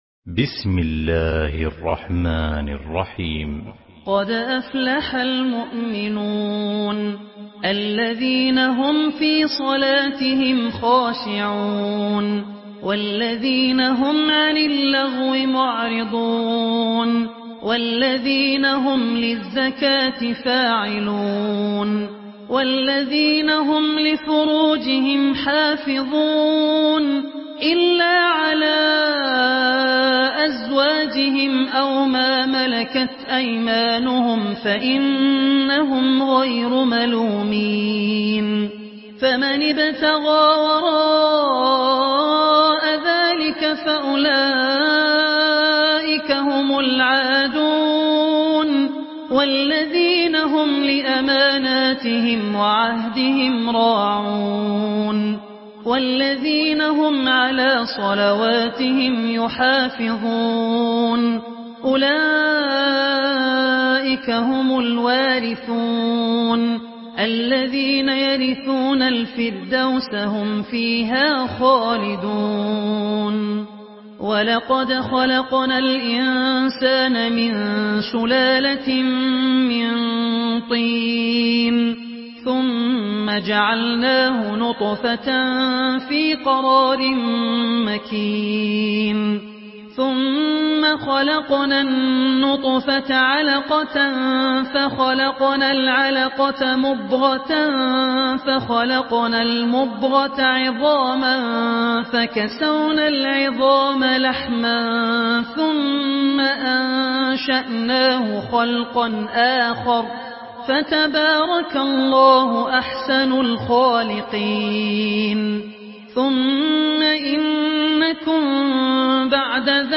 Surah Muminun MP3 by Abdul Rahman Al Ossi in Hafs An Asim narration.
Murattal Hafs An Asim